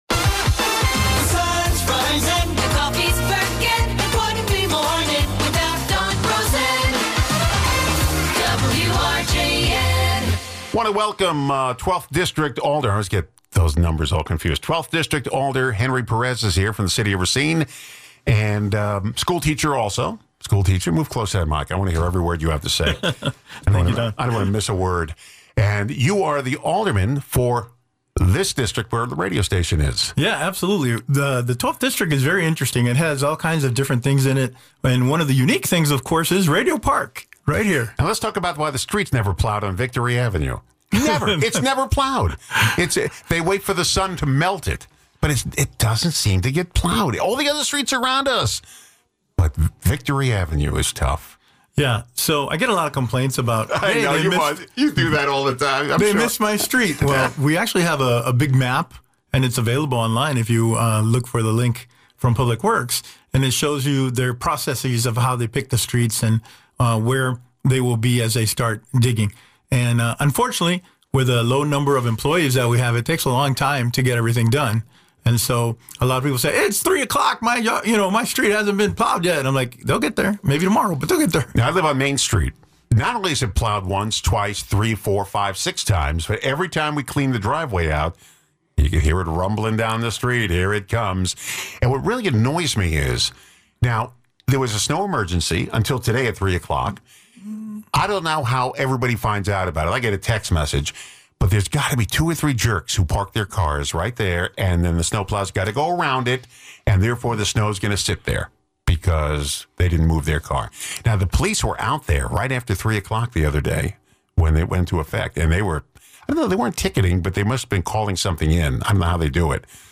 Guests: Henry Perez